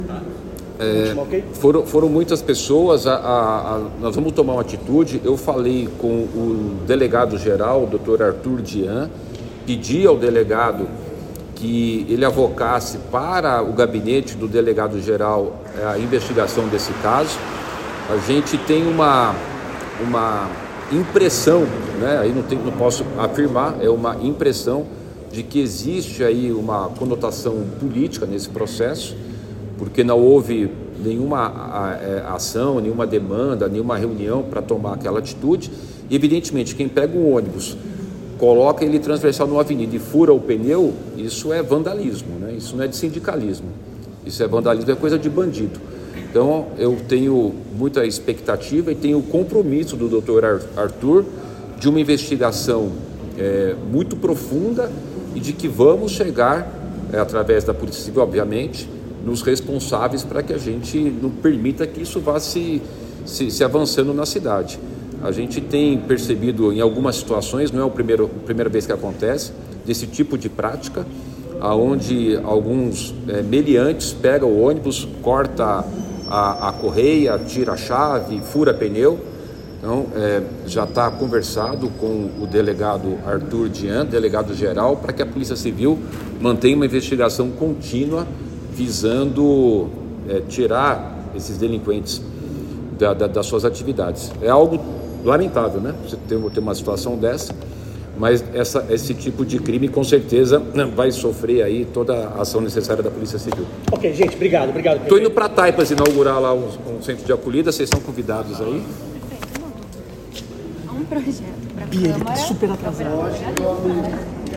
O prefeito de São Paulo, Ricardo Nunes, disse na manhã desta terça-feira, 29 de agosto de 2023, que já entrou em contato com o delegado-geral da Polícia Civil de São Paulo, Artur José Dian, para que seu gabinete cuide diretamente da onda de ataques a ônibus na capital paulista que tenham conotação sindical.